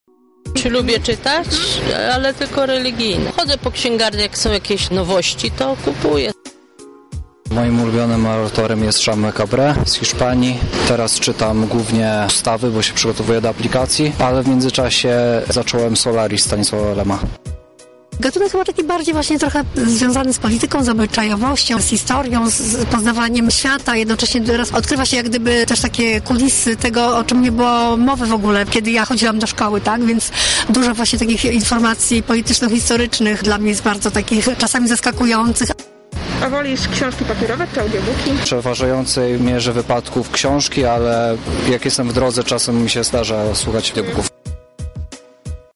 Między innymi o tym porozmawialiśmy z mieszkańcami Lublina:
Sonda książki